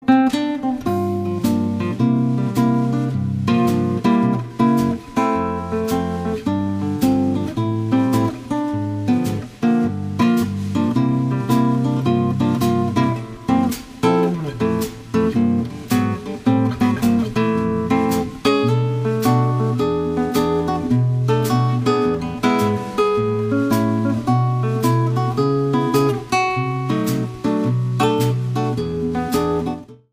Guitar
Percussions